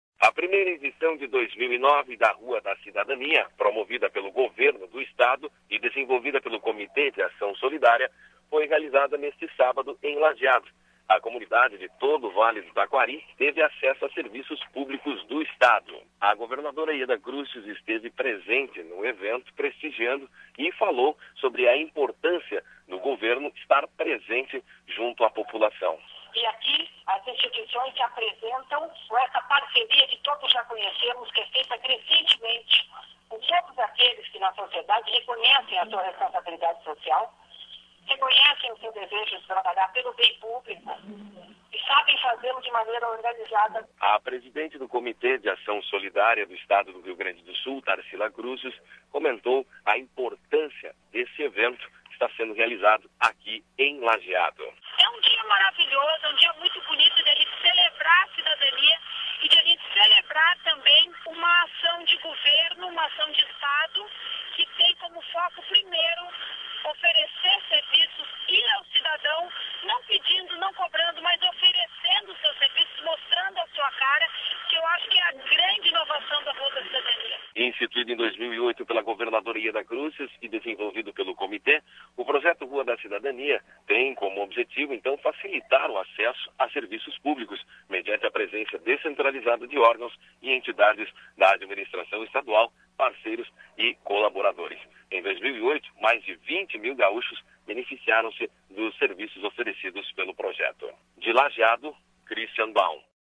A governadora Yeda Crusius abriu, na manhã deste sábado (21), em Lajeado, a primeira edição de 2009 do projeto Rua da Cidadania. O município está comemorando 156 anos de fundação.